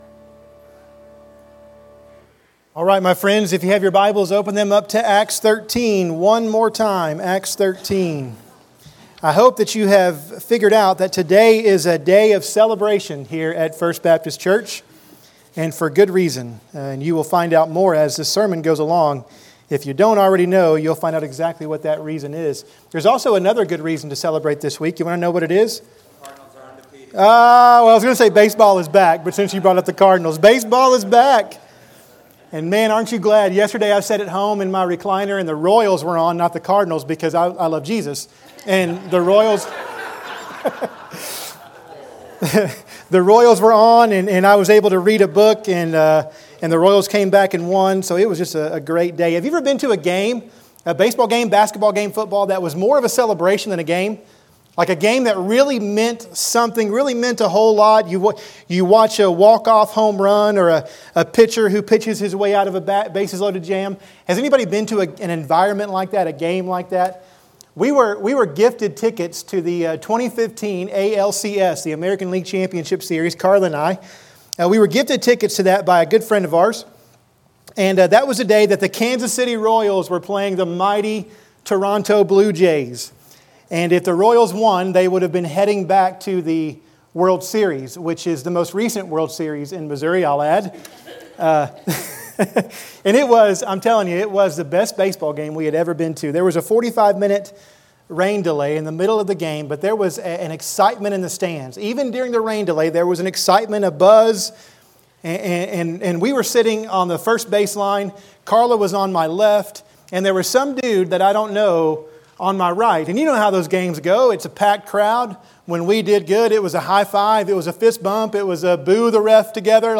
In this sermon we discover three reasons why "Gospel Goodbyes" are a cause for celebration. And we commission and send out our Renick Replant "Sent Team!"